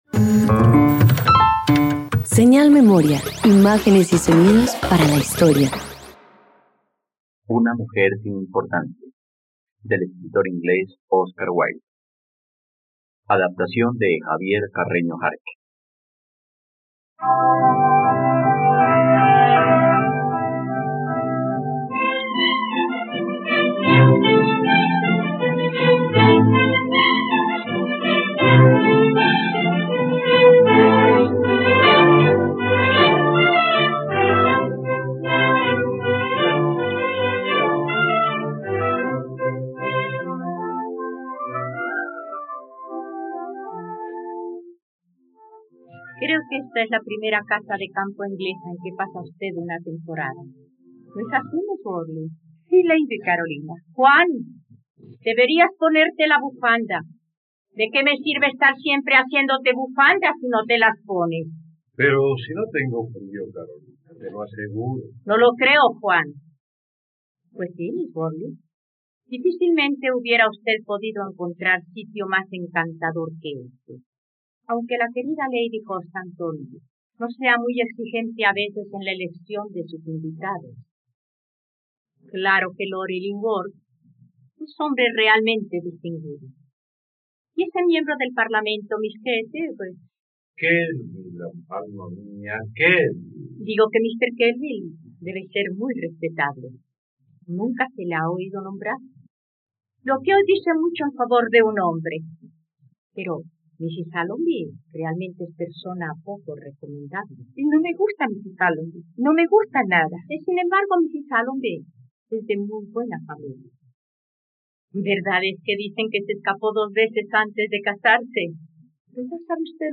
Una mujer sin importancia - Radioteatro dominical | RTVCPlay